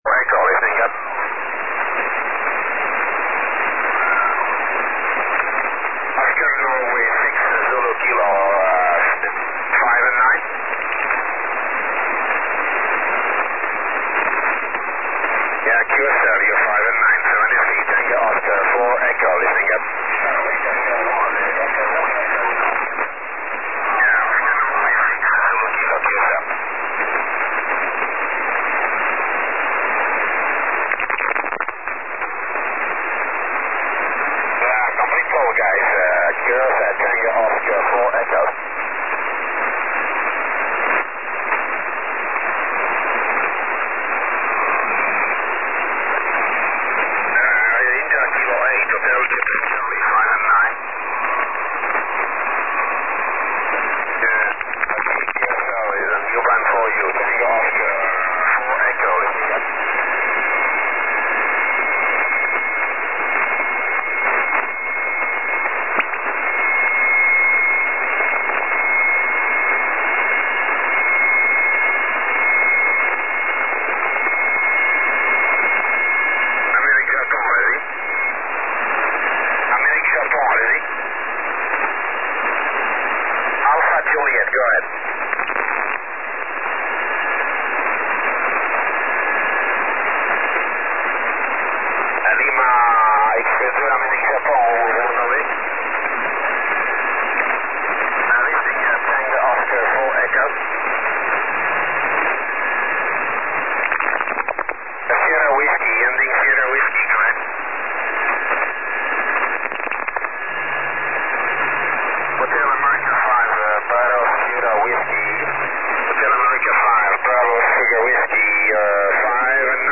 24 MHz SSB